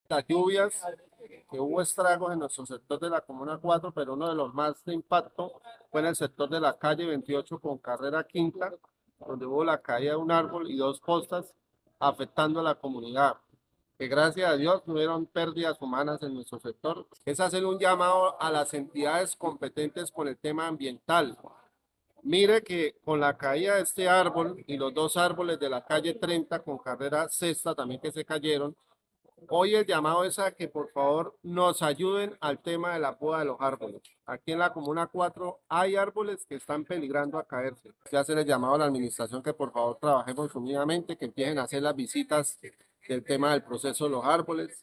Jorge Leonardo León, edil comuna 4